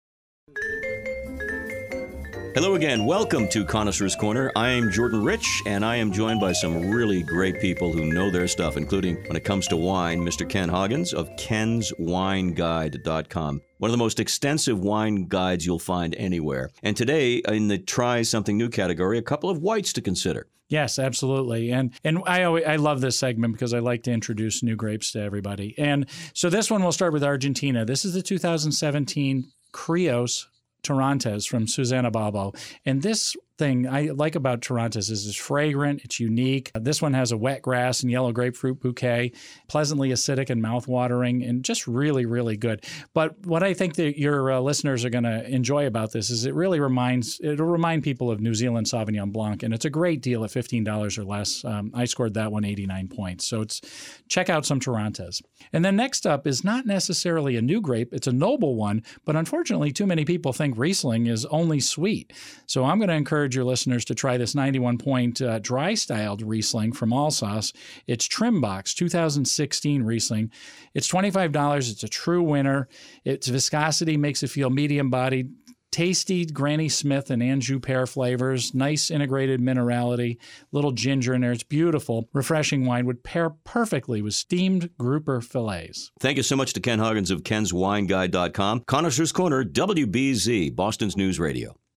This post covers some of the radio spots that played on WBZ this summer.